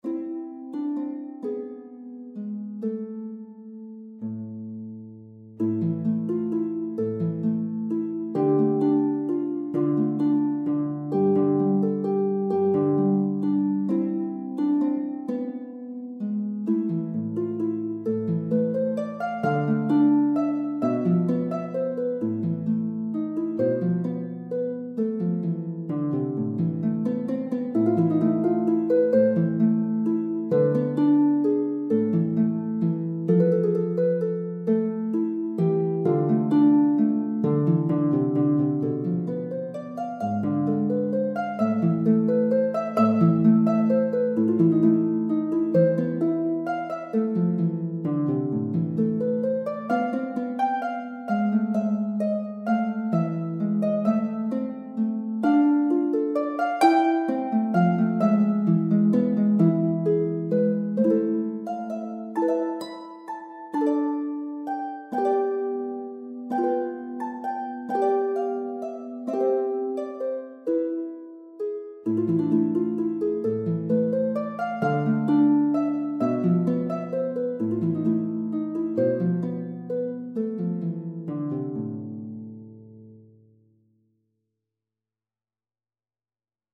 Advanced Intermediate Lever – 4 pages